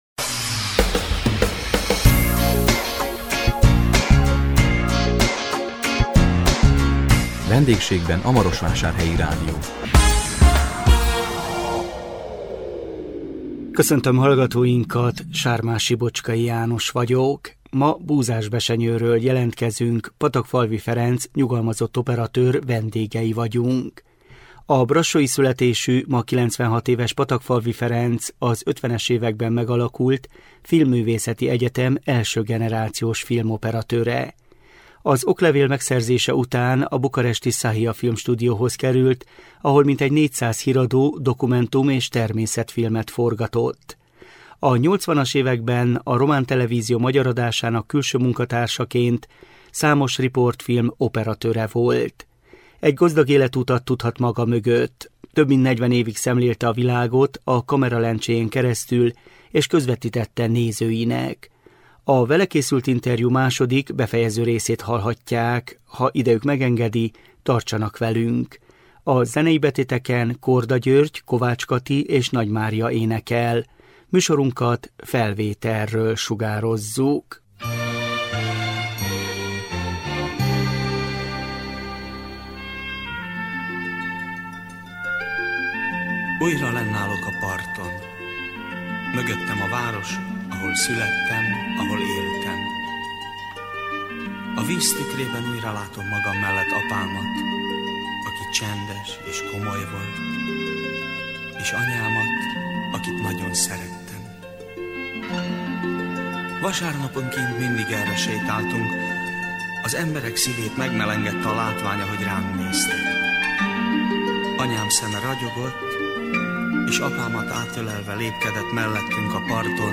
A vele készült interjú második, befejező részét hallhatják. https